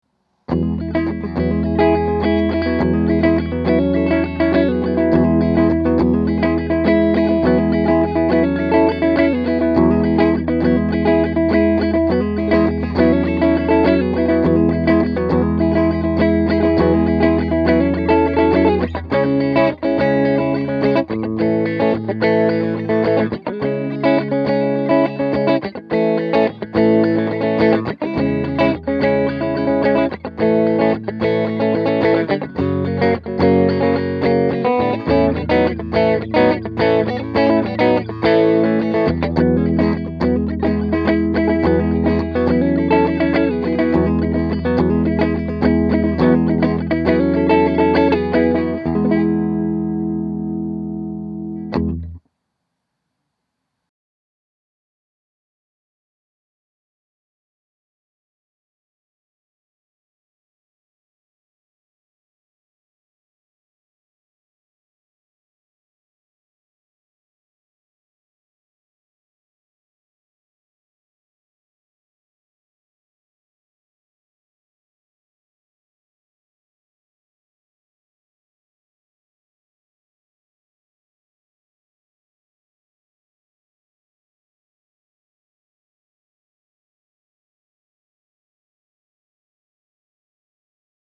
I put together some quick clips to demonstrate the sound of this guitar (all clips were played through my beloved Aracom PLX18 “plexi” clone):
Next, we’ve got the riff to the Doobie Brothers “Listen to the Music” to demonstrate the neck pickup played clean: